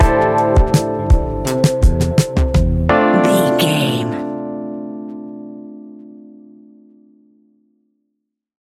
Ionian/Major
D
laid back
relaxed
Lounge
sparse
new age
chilled electronica
ambient
atmospheric
morphing